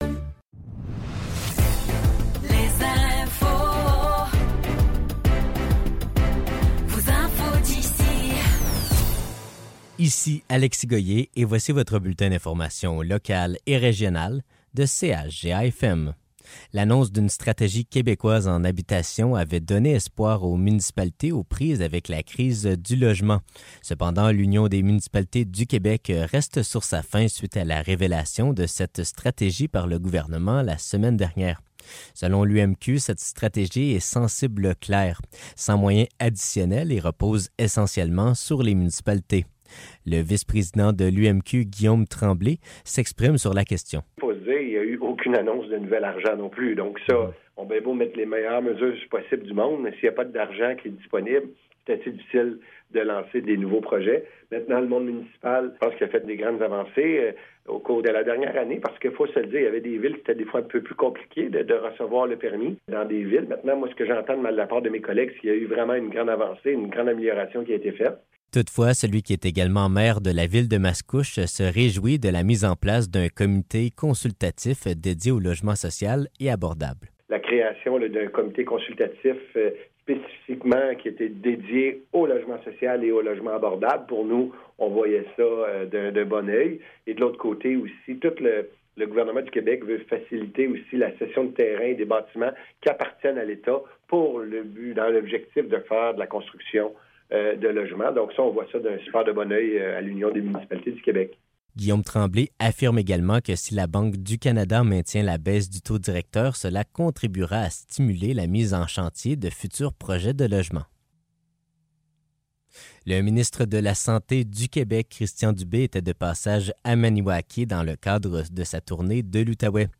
Nouvelles locales - 29 Août 2024 - 10 h